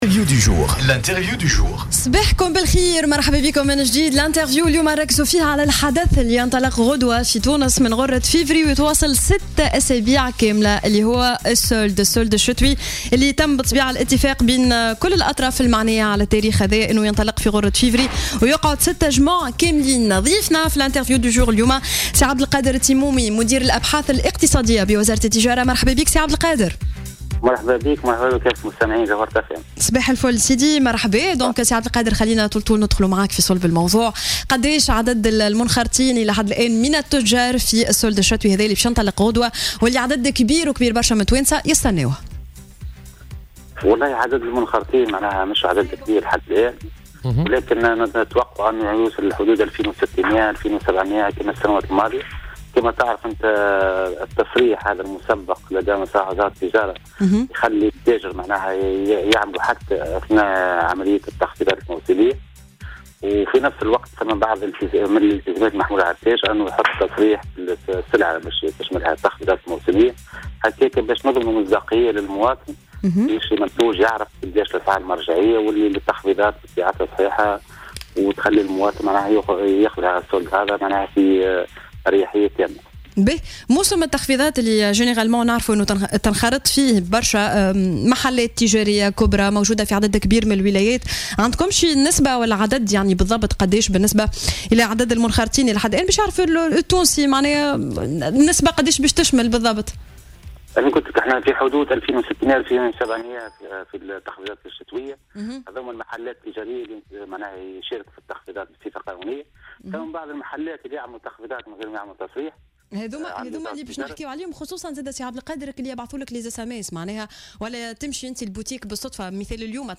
في مداخلة له على الجوهرة "اف ام" اليوم الثلاثاء